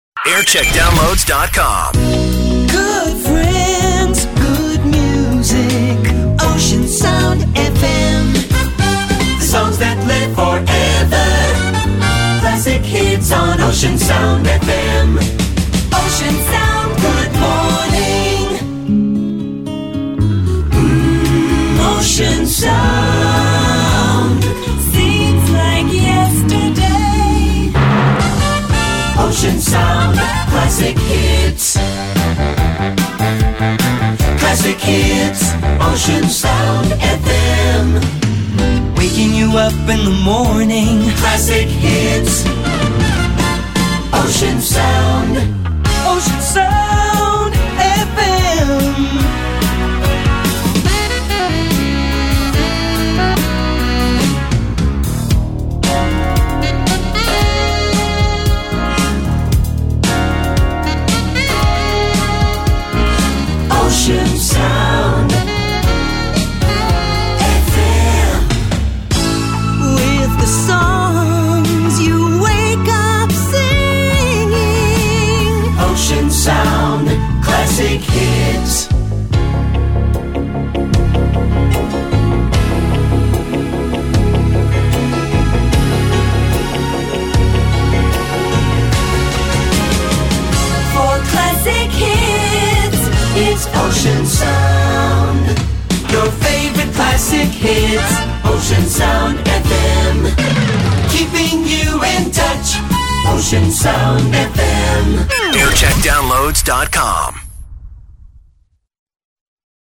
Jingle Montage